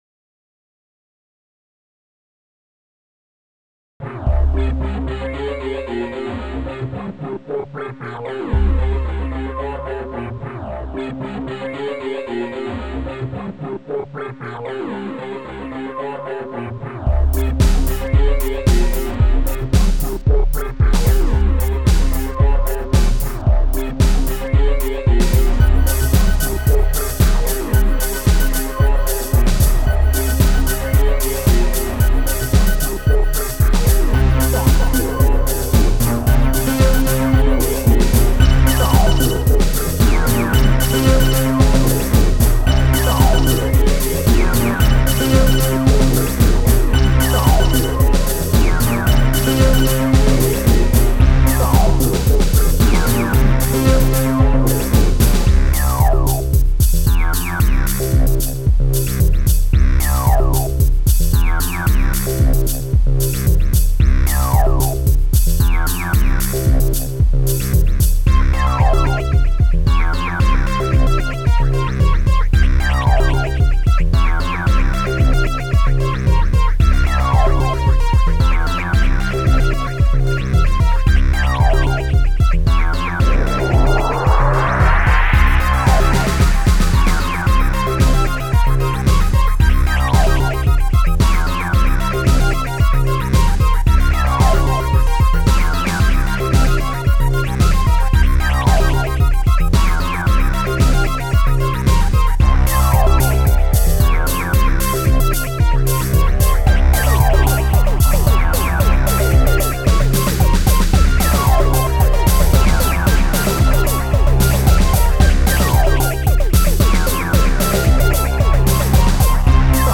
xm (FastTracker 2 v1.04)
except for the congas,
cymbals, b-drum, and